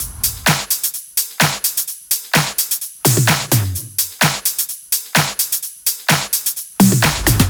VFH2 128BPM Unimatrix Kit 3.wav